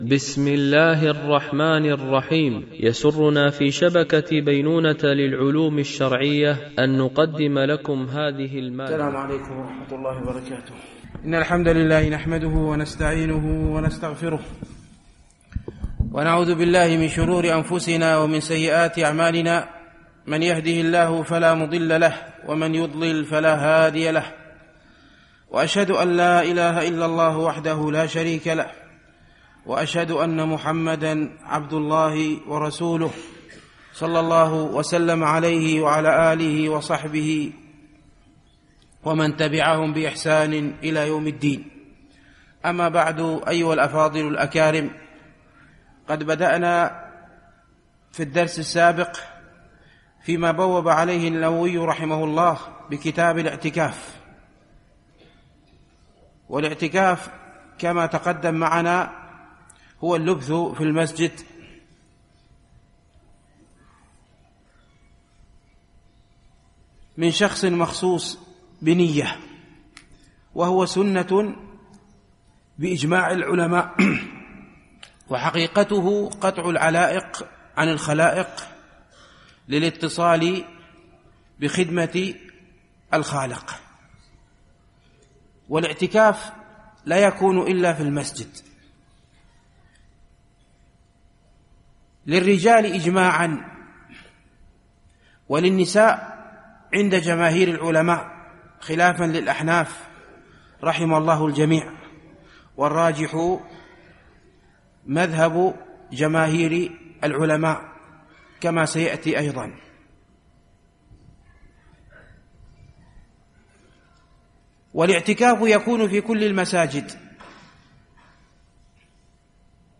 شرح رياض الصالحين – الدرس 336 ( الحديث 1276 - 1278 )